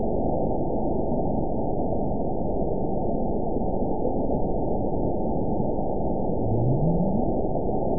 event 922155 date 12/27/24 time 18:59:48 GMT (5 months, 3 weeks ago) score 9.54 location TSS-AB04 detected by nrw target species NRW annotations +NRW Spectrogram: Frequency (kHz) vs. Time (s) audio not available .wav